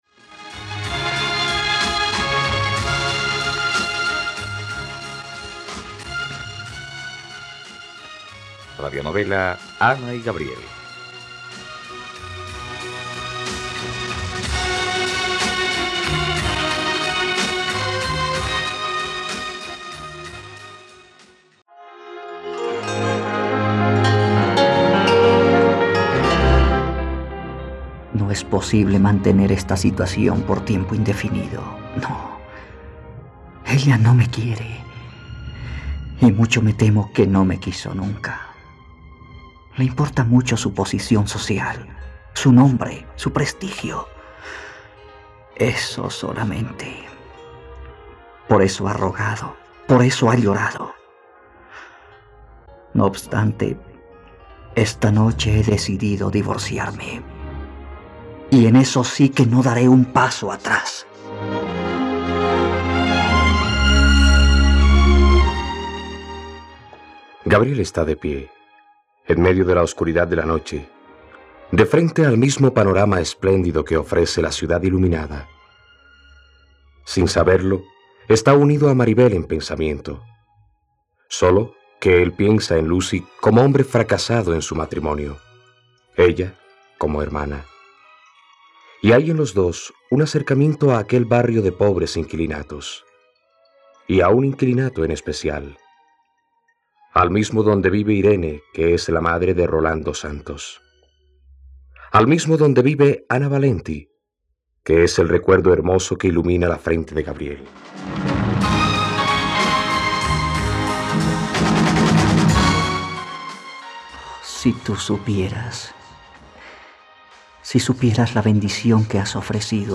..Radionovela. Escucha ahora el capítulo 30 de la historia de amor de Ana y Gabriel en la plataforma de streaming de los colombianos: RTVCPlay.